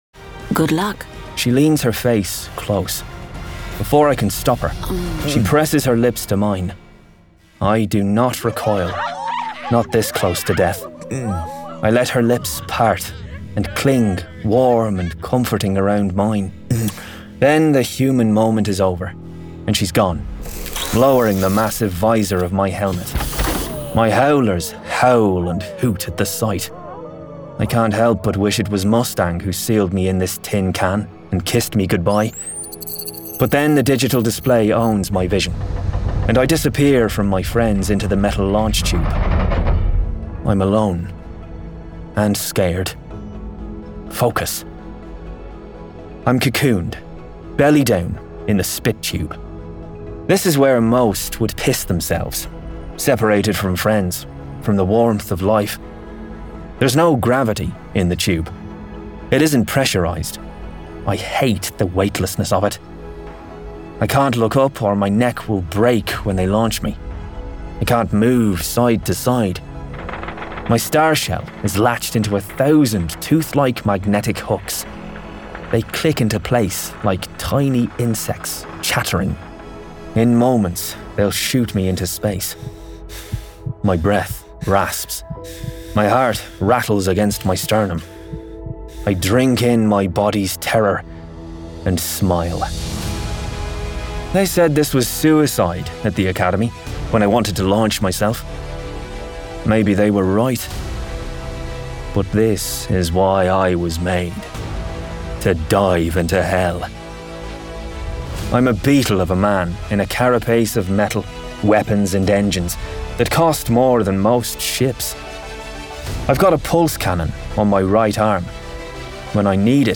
Full Cast. Cinematic Music. Sound Effects.
[Dramatized Adaptation]
Adapted from the novel and produced with a full cast of actors, immersive sound effects and cinematic music!